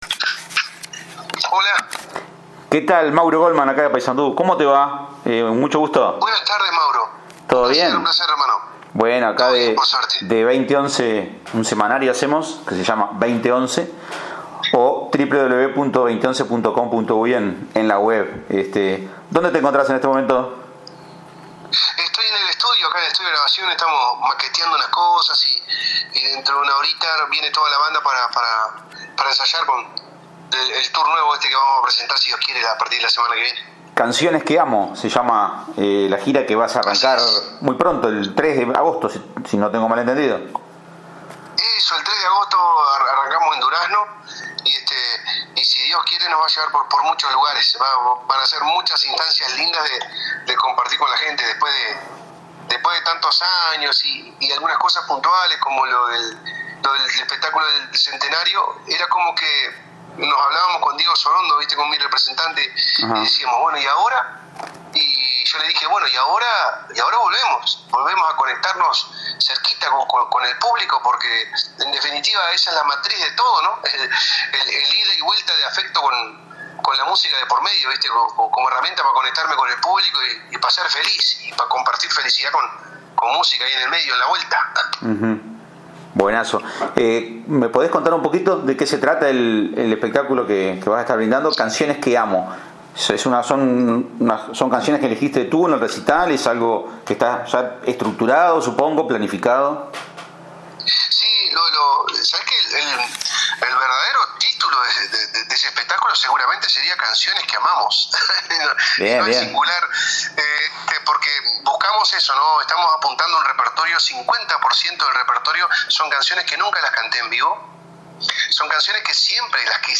En el estudio de grabación y esperando a la banda para ensayar, atendió la llamada de 20Once para hablar sobre el tour que